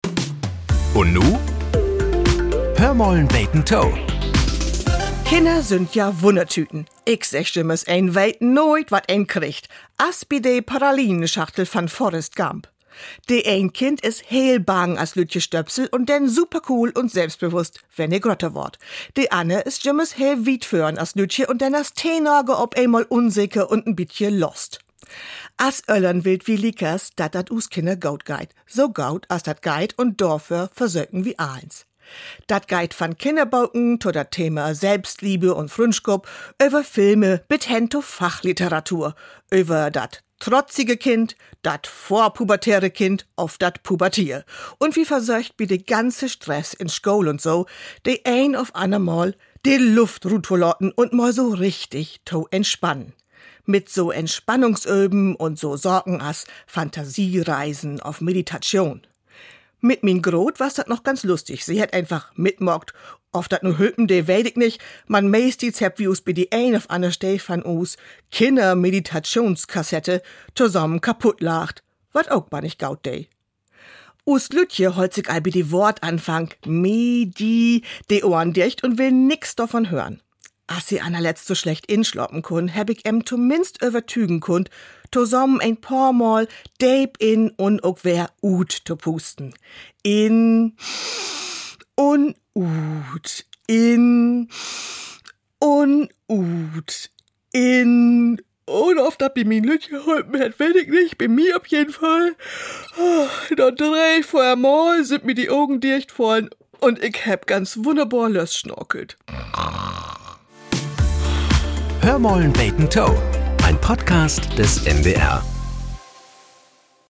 Die plattdeutsche Morgenplauderei "Hör mal 'n beten to" gehört seit mehr als 60 Jahren zum Alltag in Norddeutschland. Hier werden die Wunderlichkeiten des Alltags betrachtet. So klingt es, wenn wir Norddeutschen uns selbst auf die Schippe nehmen - liebevoll bis spöttisch, selten mit dem Finger in schmerzenden Wunden, aber immer an Stellen, an denen wir kitzelig sind.